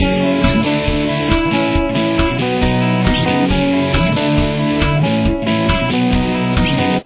Solo
Rhythm Guitars: